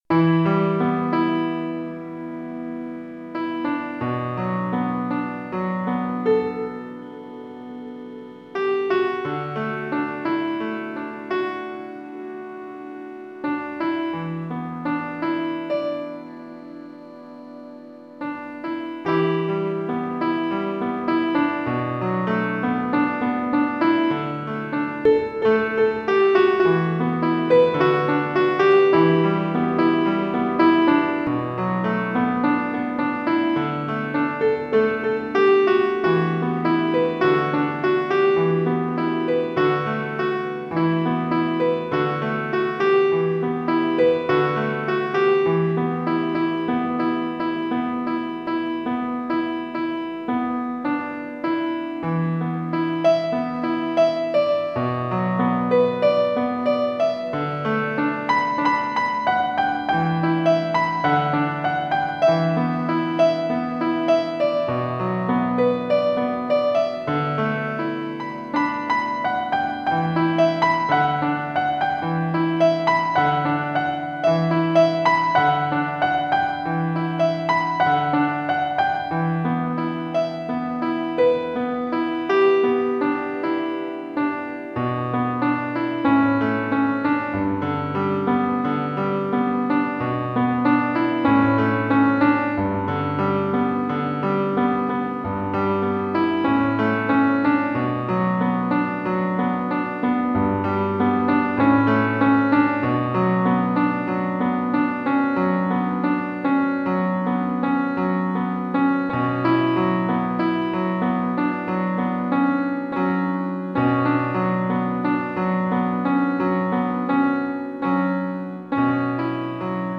Es una prueba, con algún efectillo ambiente 🙂